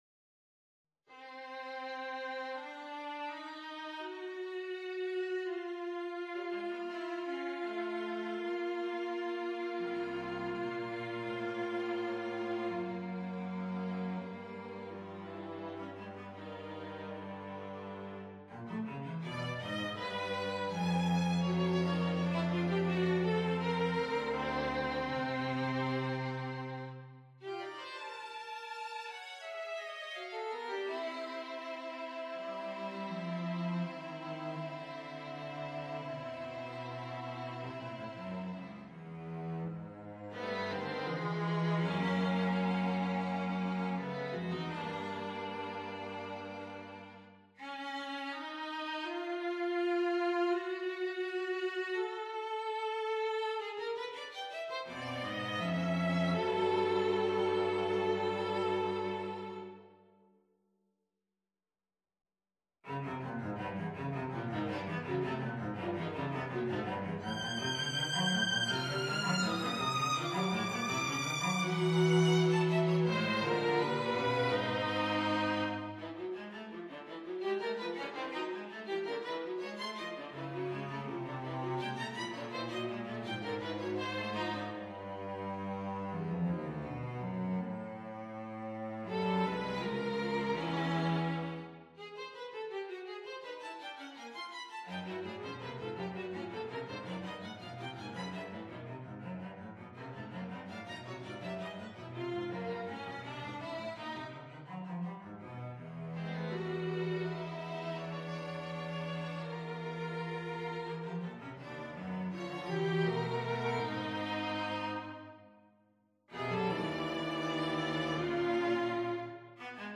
on a purpose-selected tone row
Allegro appassionato assai